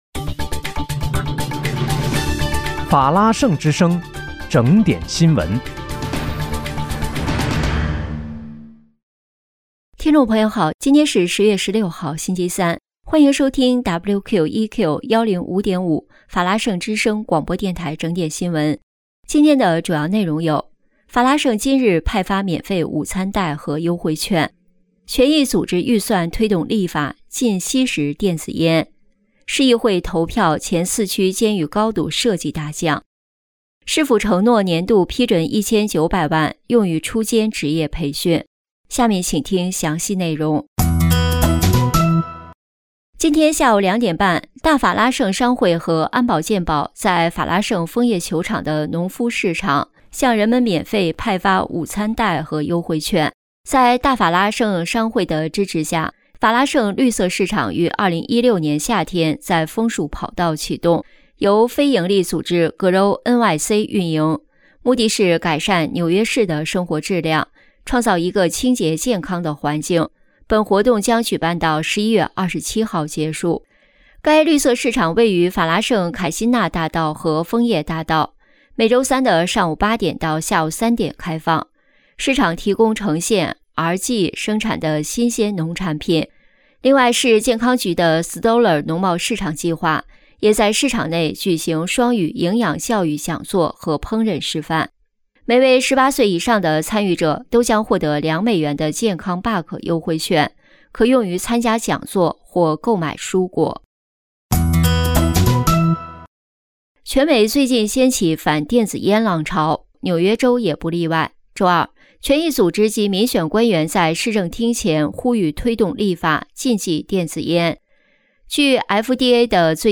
10月16日（星期三）纽约整点新闻